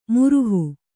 ♪ muruhu